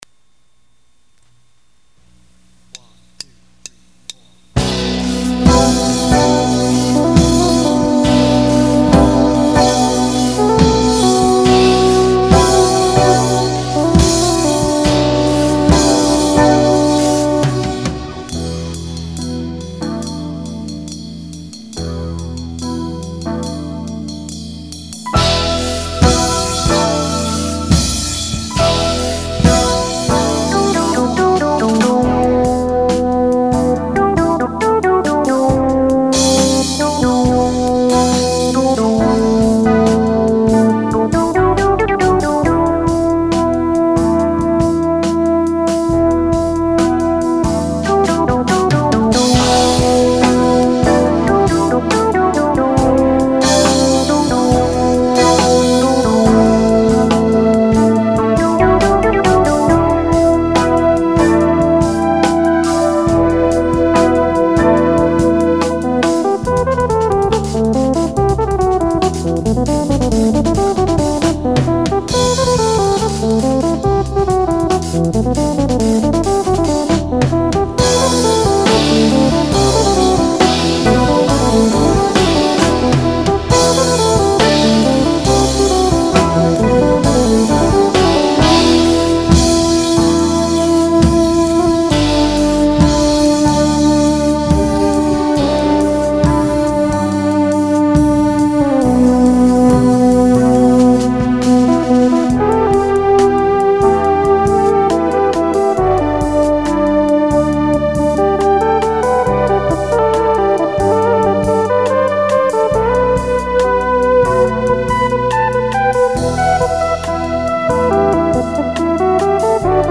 But I begged and BEGGED and promised not to overplay and keep it just like he wanted me to, to match his keyboard bass lines.